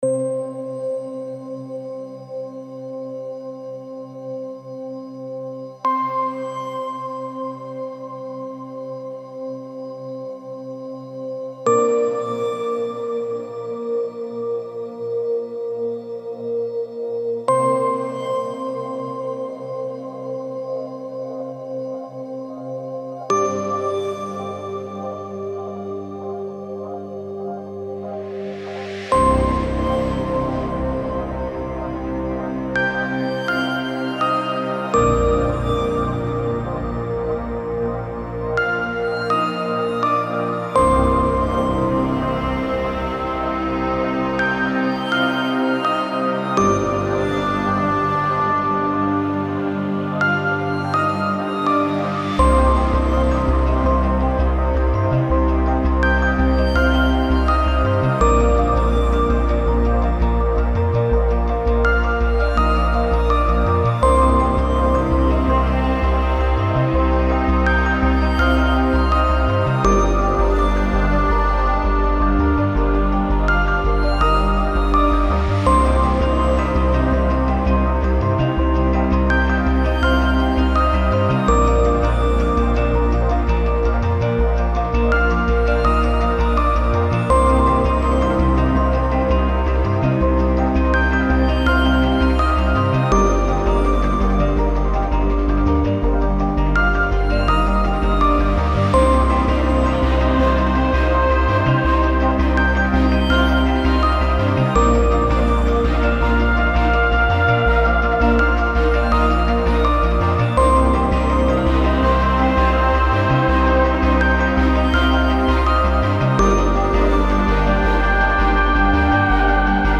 Easy listening
رازآلود